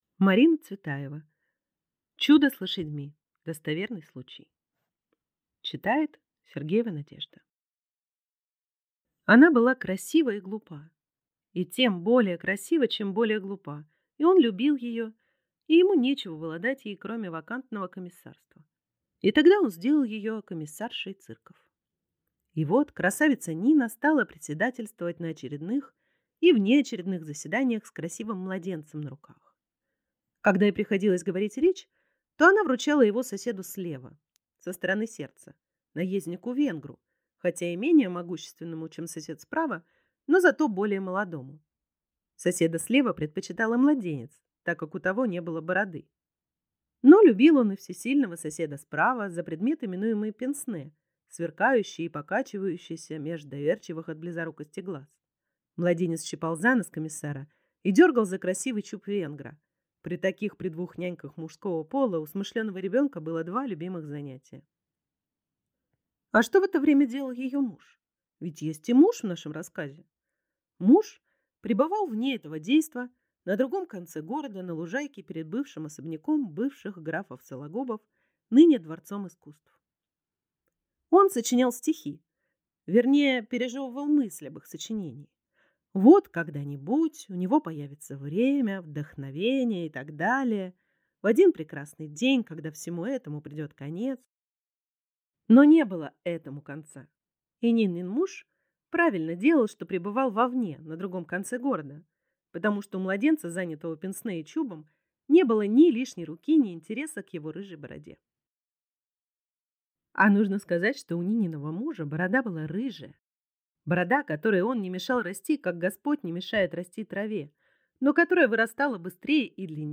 Аудиокнига Чудо с лошадьми | Библиотека аудиокниг